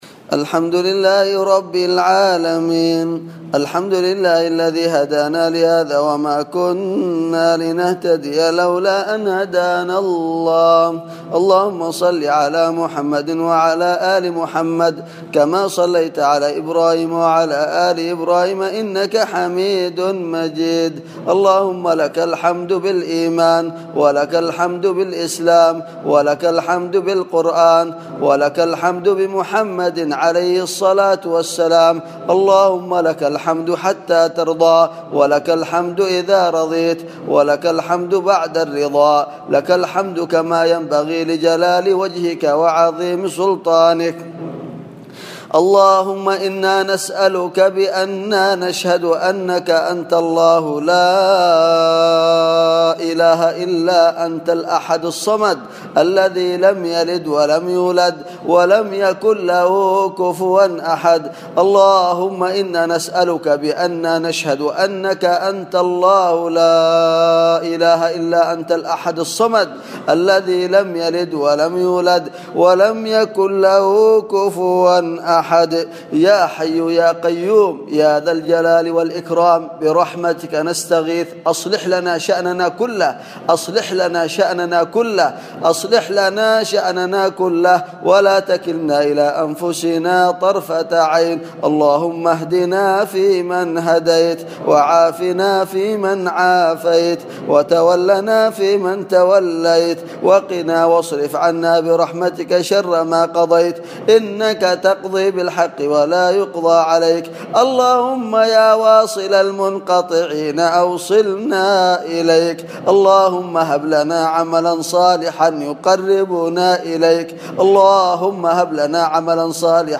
دعاء ليلة القدر 1437هـ